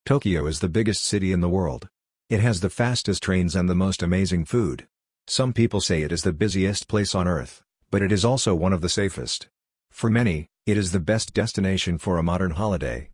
🎙 Shadowing Practice: The Ultimate City